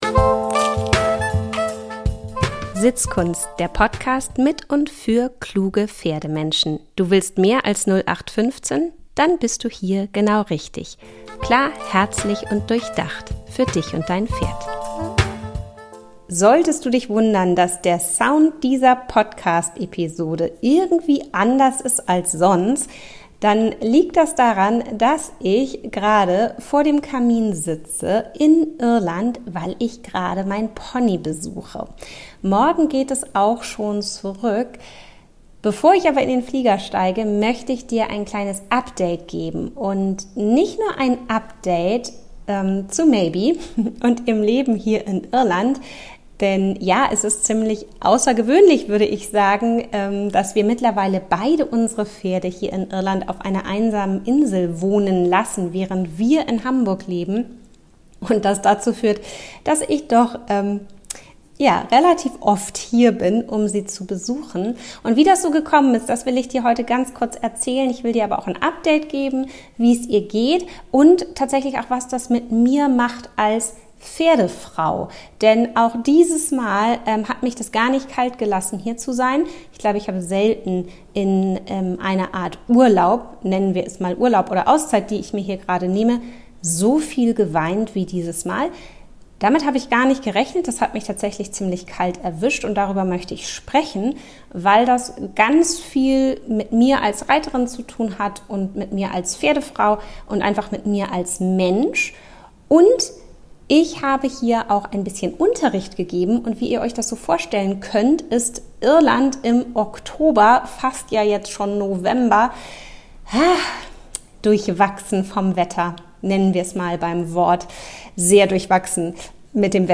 Wie es ihr und auch mir mittlerweile geht und wie sich ihre Gesundheit und unsere Beziehung verändert hat, darüber spreche ich in dieser sehr persönlichen und für dich live vor Ort aufgenommenen Podcast-Episode.